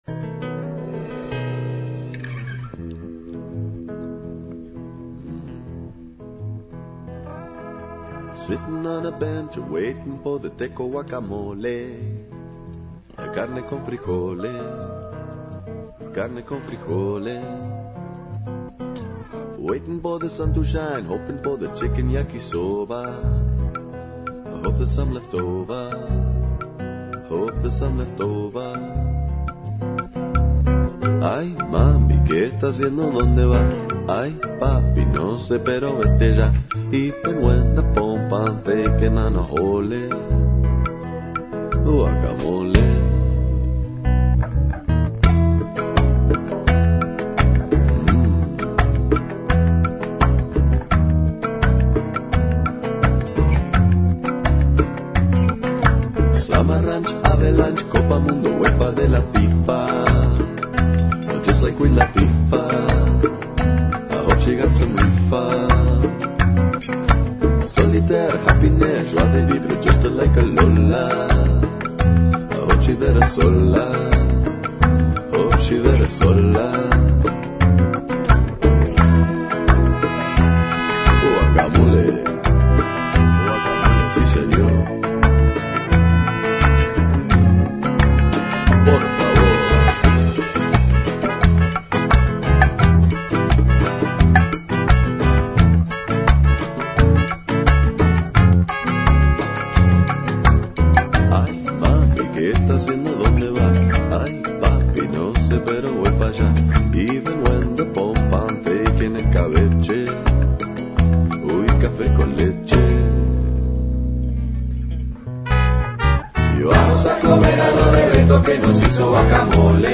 Entrevista a Fernando Rizzi, Defensor del Pueblo de Mar del Plata sobre la audiencia publica que se llevó acabo ayer en Mar del Plata por los tarifazos. Además hablo de la suspensión del fallo del juez Arias sobre el incremento en la luz.